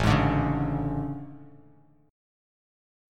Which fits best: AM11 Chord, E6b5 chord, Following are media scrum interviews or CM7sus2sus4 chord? AM11 Chord